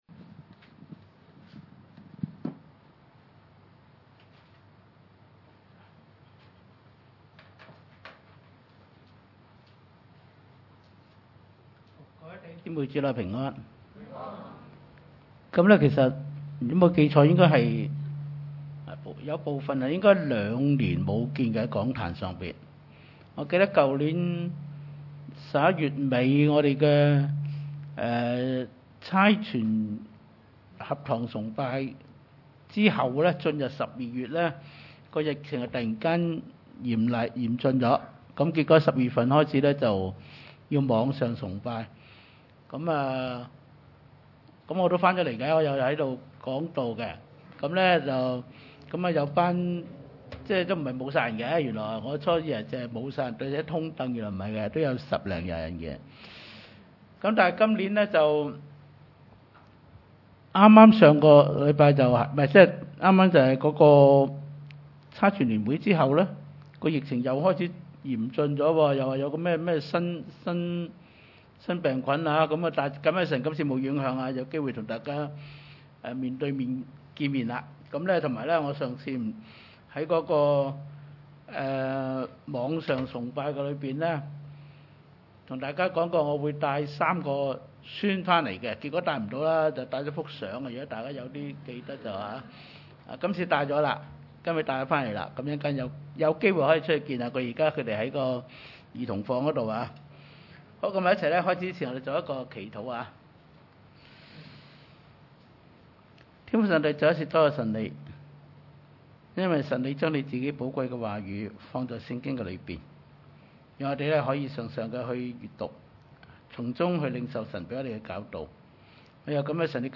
經文: 約翰福音21:18-25 崇拜類別: 主日午堂崇拜 21:18 我實實在在地告訴你，你年少的時候，自己束上帶子，隨意往來；但年老的時候，你要伸出手來，別人要把你束上，帶你到不願意去的地方。」